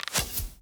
Bow Attack 1.ogg